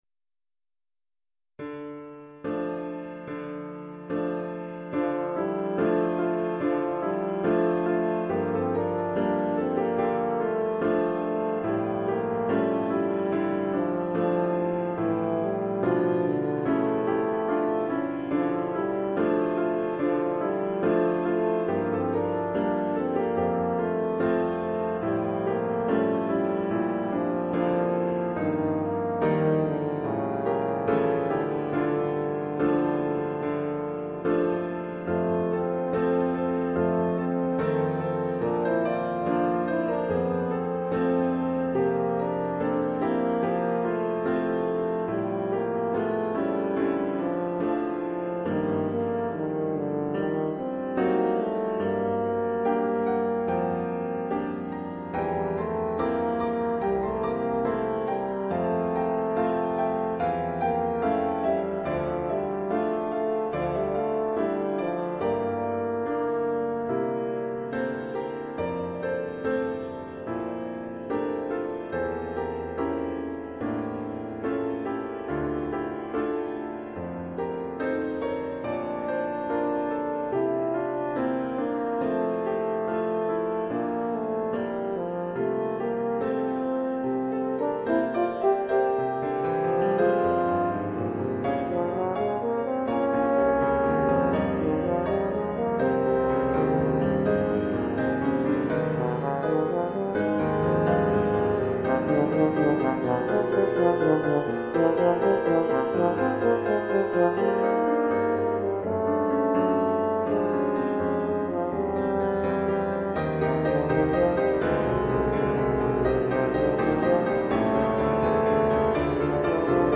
Oeuvre pour saxhorn alto et piano.